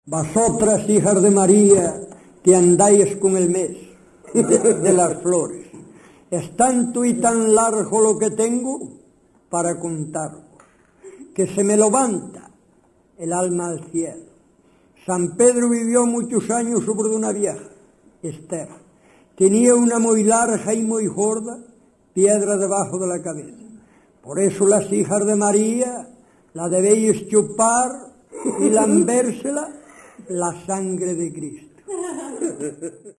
Tipo de rexistro: Musical
Áreas de coñecemento: LITERATURA E DITOS POPULARES > Cantos narrativos
Lugar de compilación: Frades - Moar (Santaia)
Soporte orixinal: Casete